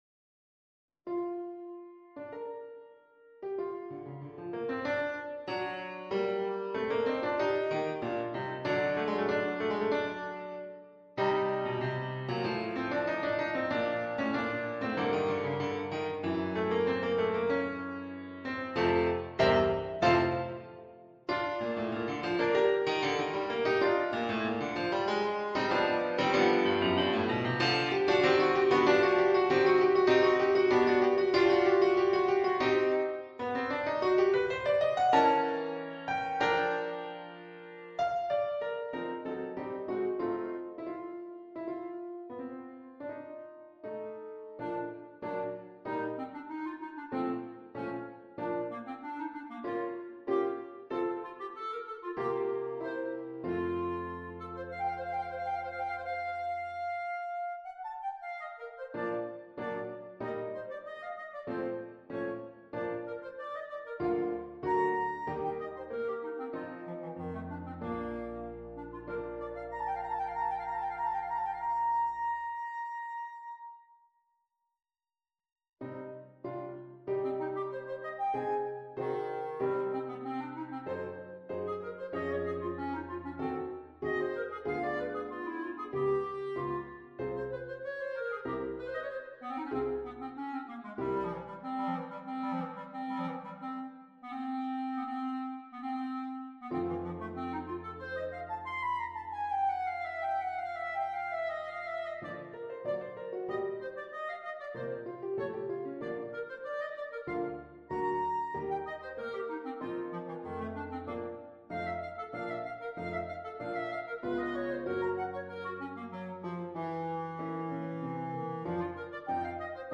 Per clarinetto e pianoforte
Grande fantasia per clarinetto e pianoforte